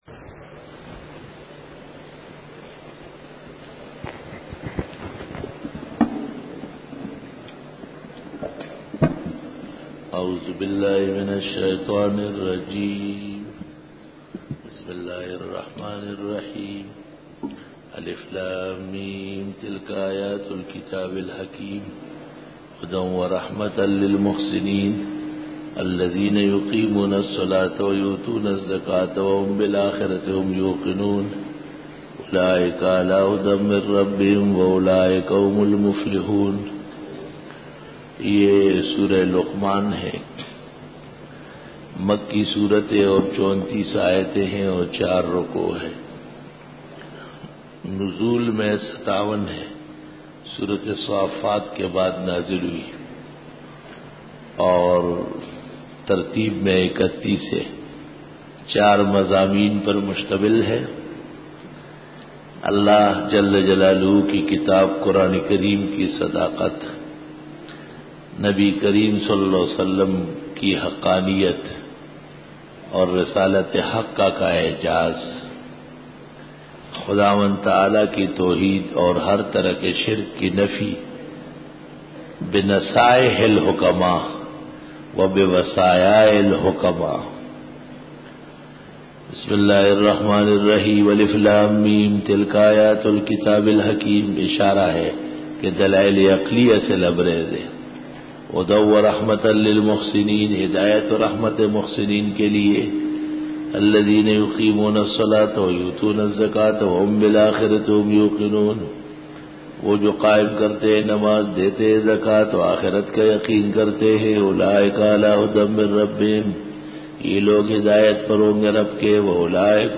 سورۃ لقمان رکوع-01 Bayan